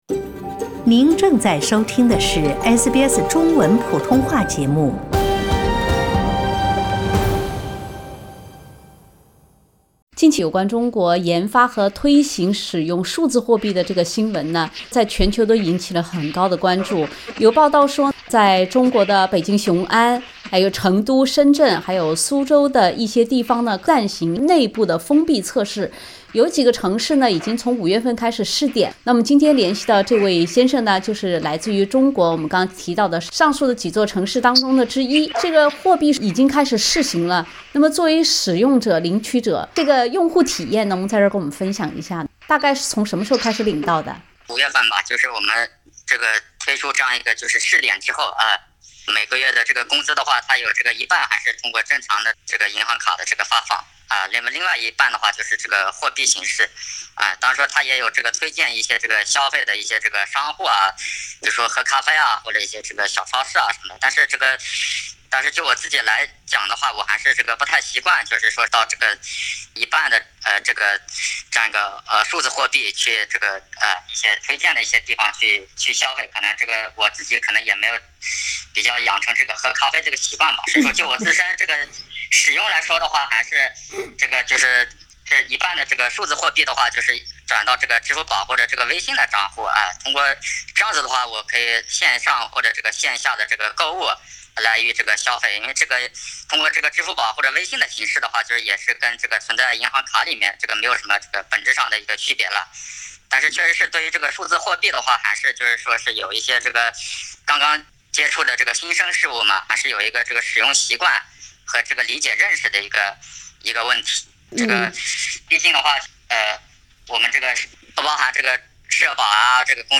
SBS普通话节目组记者经多方努力联系到了一位中国首批数字货币工资领取者,匿名分享用户体验。